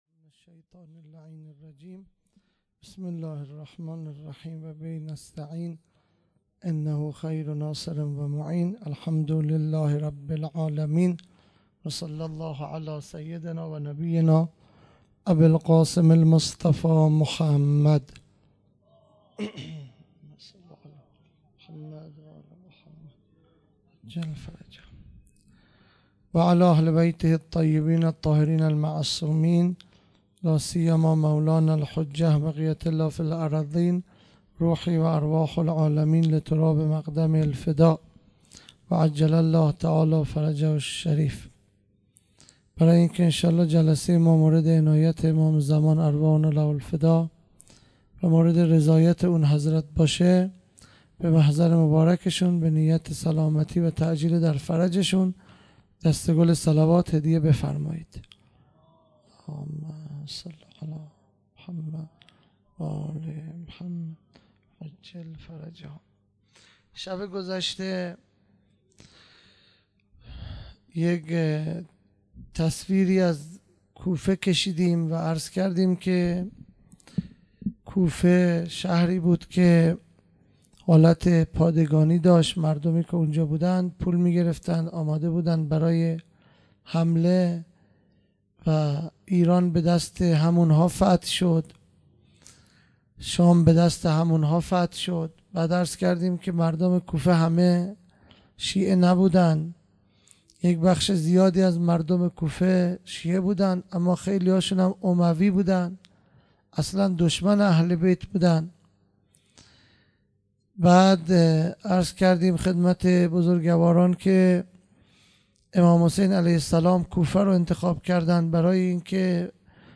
خیمه گاه - هیئت بچه های فاطمه (س) - سخنرانی